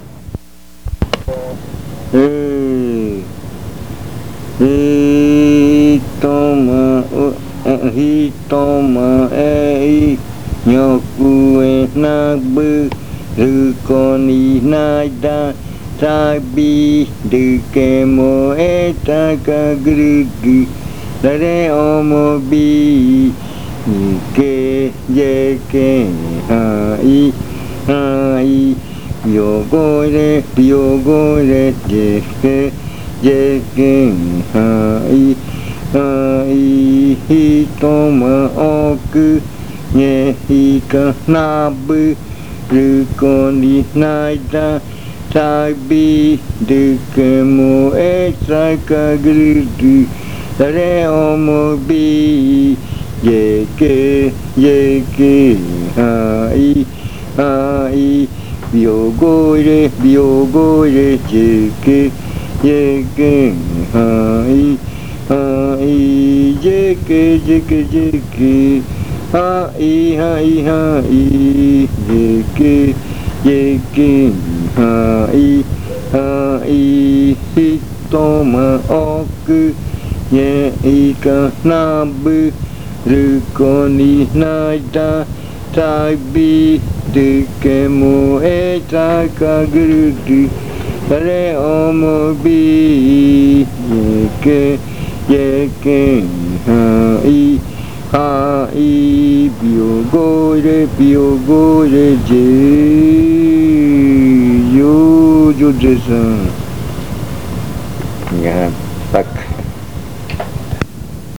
Leticia, Amazonas
Canto con adivinanza. Dice que cuando vine por el camino, los puercos del monte venían gruñendo.
Chant with a riddle. It says that when I came down the road, the peccaries came grunting.